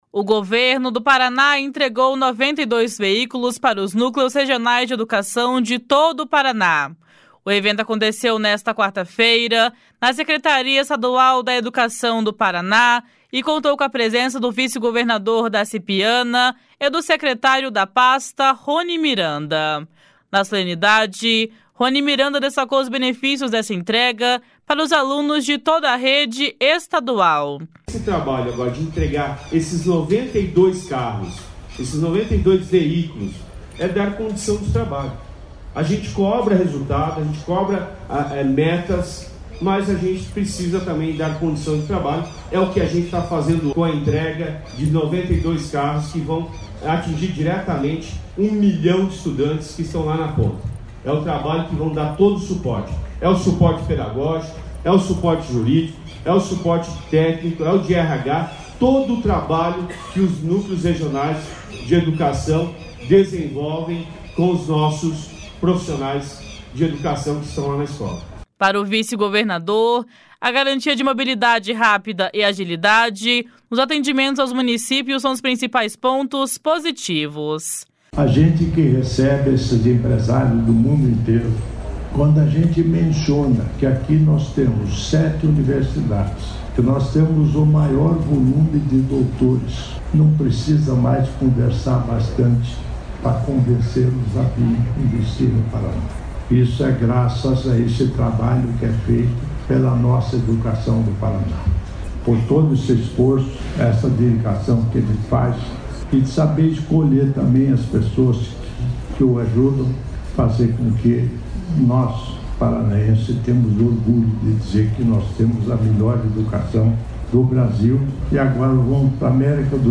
Na solenidade, Roni Miranda destacou os benefícios dessa entrega para os alunos de toda a rede estadual.
Para o vice-governador, a garantia de mobilidade rápida e agilidade nos atendimentos aos municípios são os principais pontos positivos.